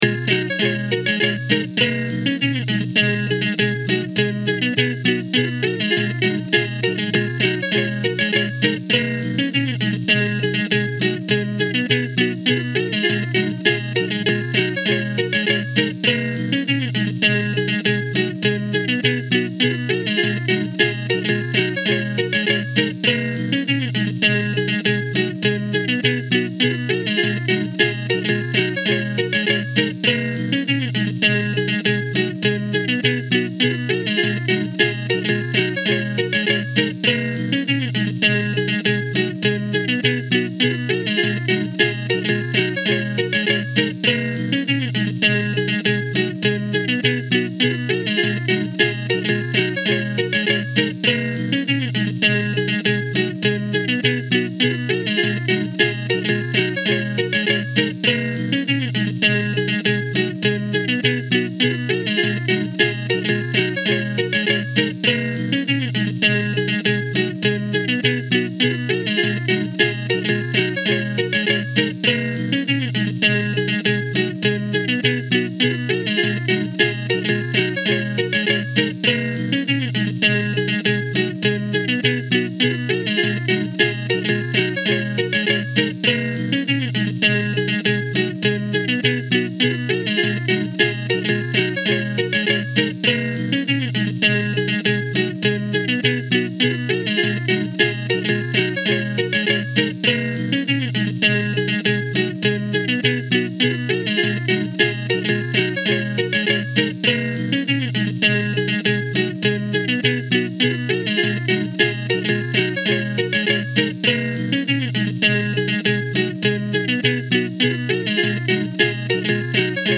this is our first song to date, its a loop and goes on